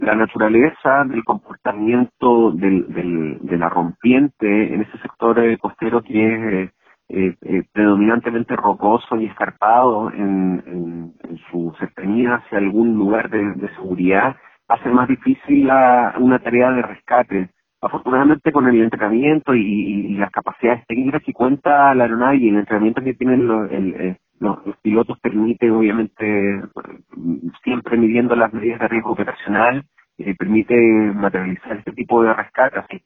En cuanto al rescate, el representante de la zona naval explicó que siempre se genera riesgo a la hora de realizar este tipo de acciones en condiciones adversas como las registradas este viernes.